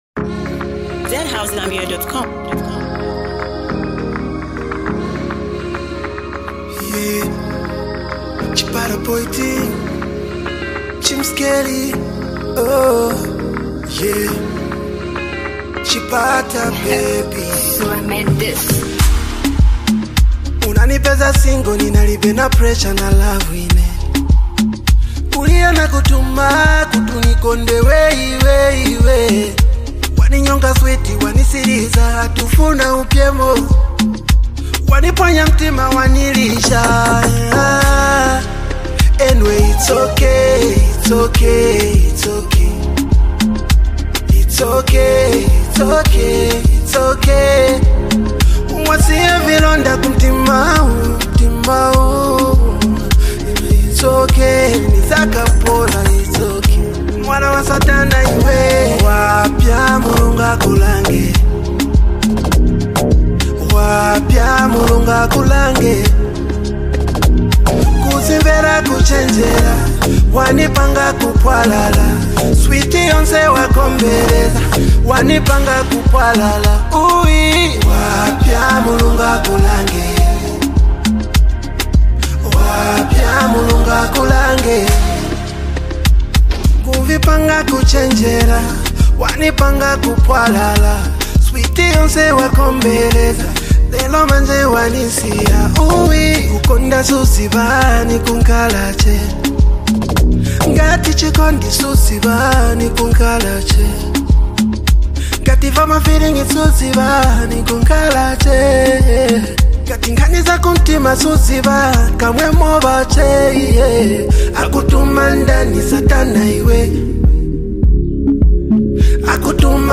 Raw, emotional, and powerful